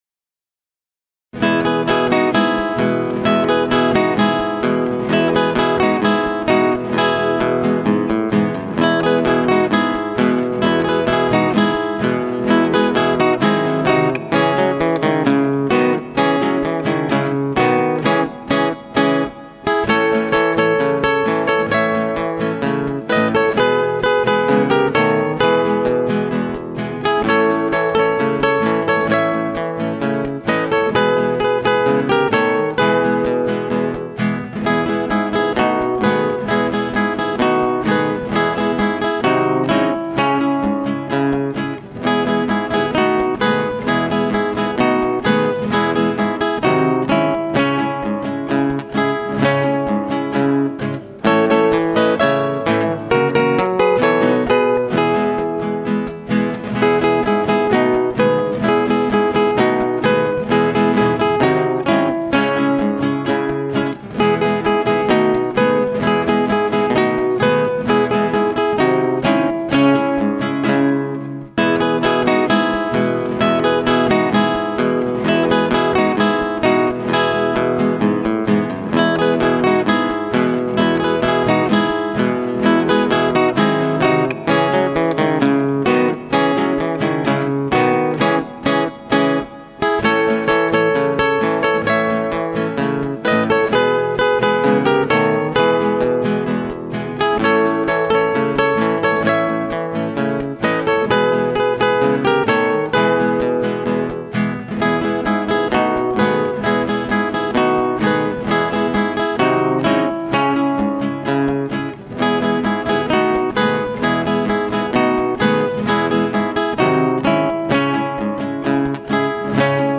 4 Gitarren